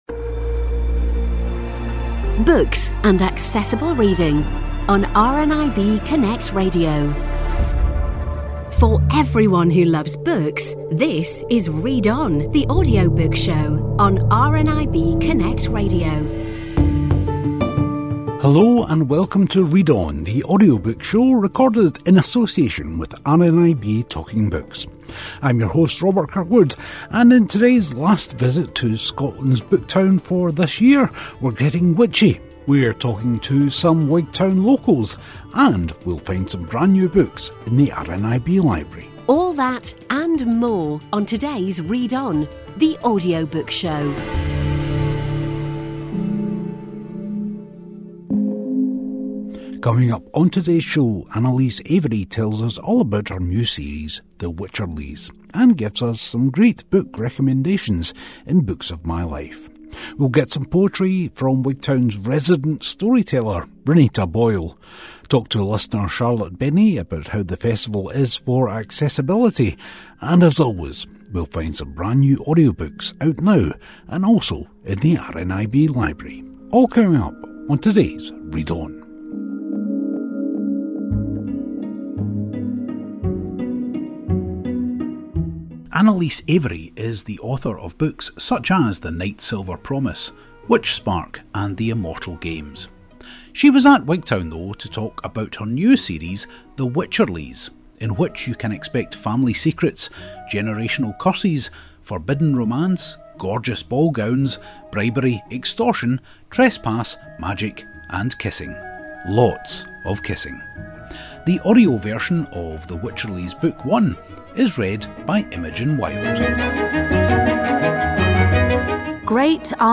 In the final show this year recorded in Scotland's Book Town